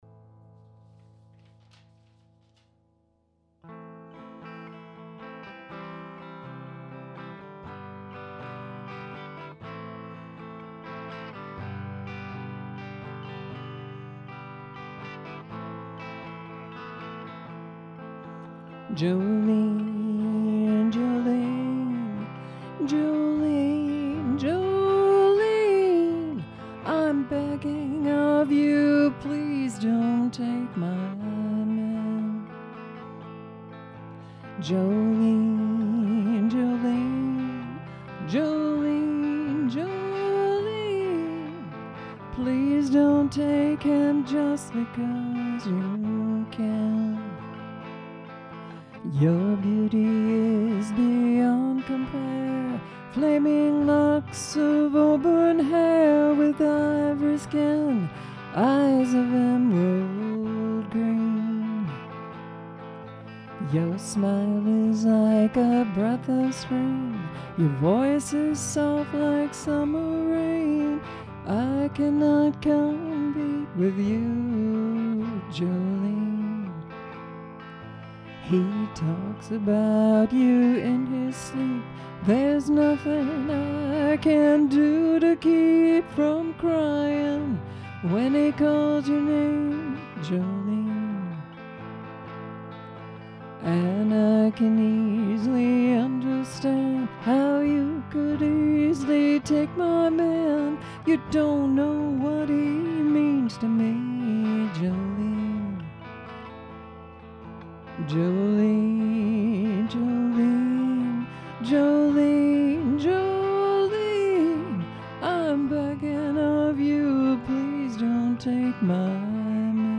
Update more emotion, higher quality Shur Mic (1/12/26)